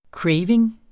Udtale [ ˈkɹεjveŋ ]